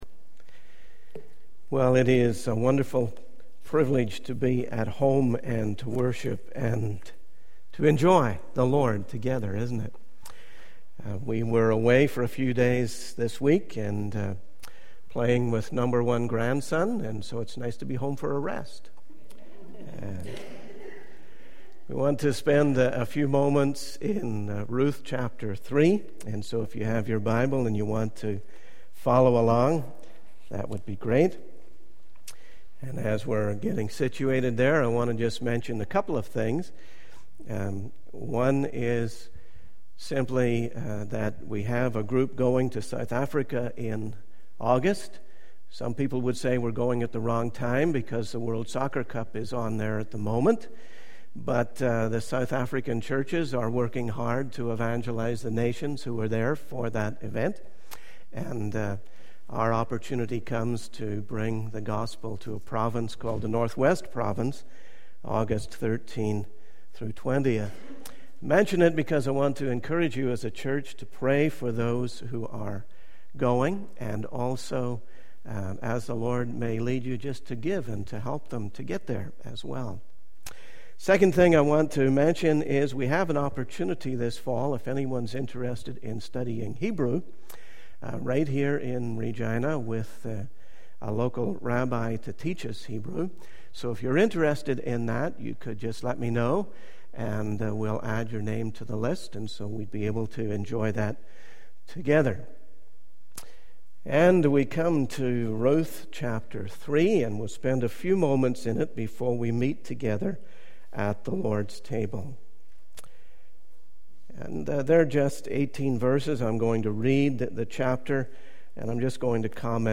In this sermon, the speaker emphasizes the faithfulness and work of God in the lives of believers. The speaker highlights the trust factor between God and His people, using the example of Ruth resting in God's promise.